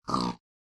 pig2.ogg